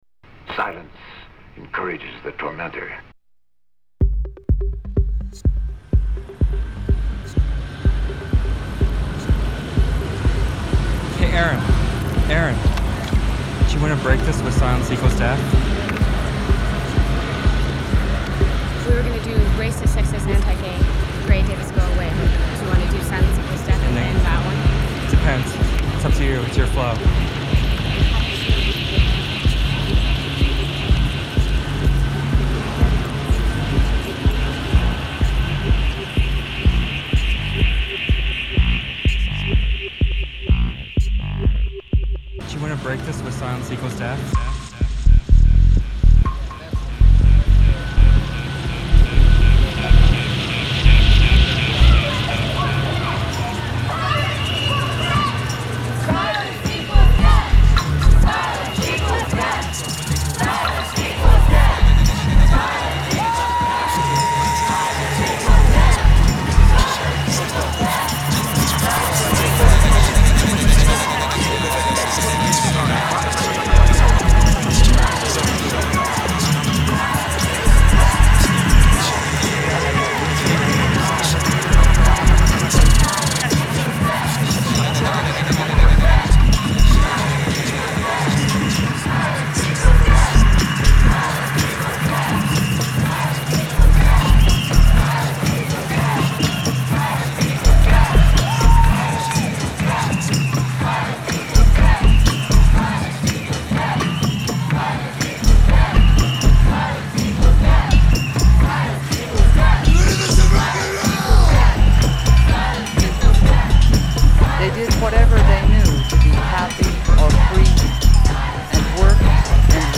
Queer electronica artists Lezzies On X